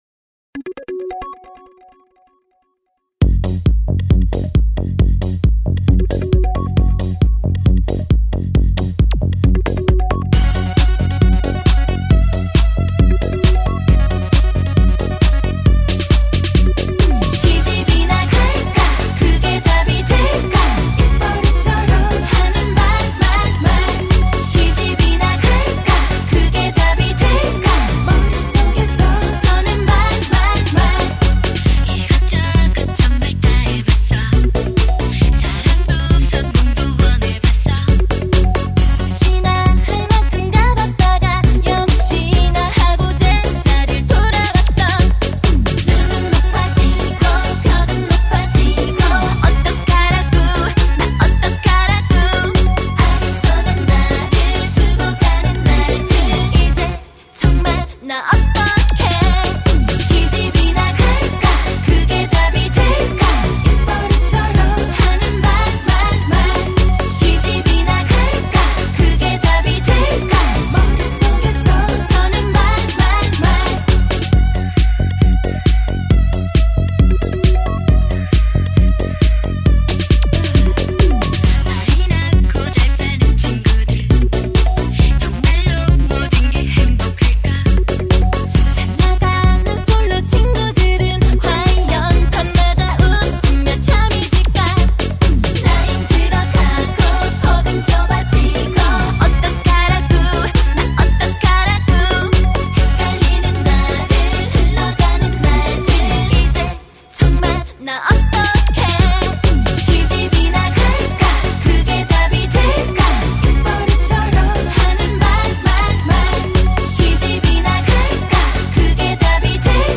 가요 >댄스팝